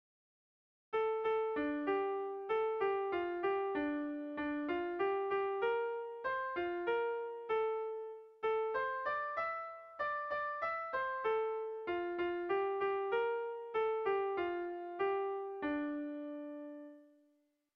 Erlijiozkoa
Lauko handia (hg) / Bi puntuko handia (ip)
AB